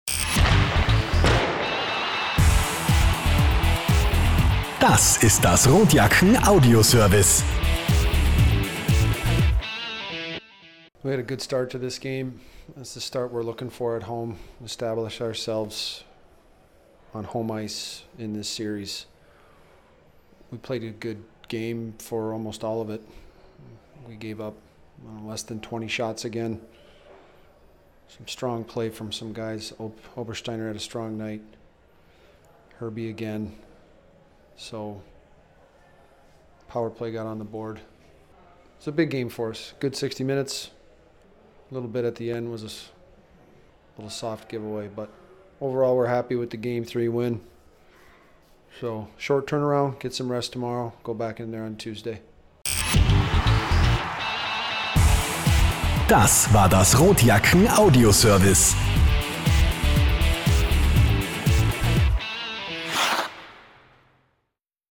Post Game (SF #3)
Heidi Horten-Arena, Klagenfurt, AUT, 4.406 Zuschauer